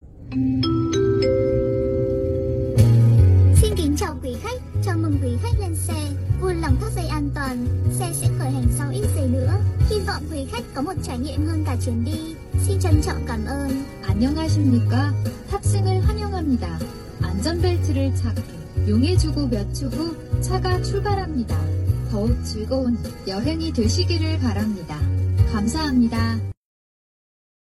Âm Thanh Chào Khởi Động Carplay (Tiếng Hàn, Giọng nữ)
Thể loại: Tiếng chuông, còi
am-thanh-chao-khoi-dong-carplay-tieng-han-giong-nu-www_tiengdong_com.mp3